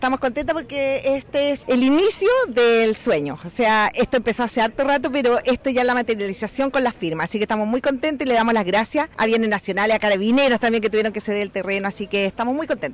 La directora del Servicio de Salud del Reloncaví, Bárbara del Pino, destacó el hito e indicó que “estamos contentos porque este es el inicio del sueño”.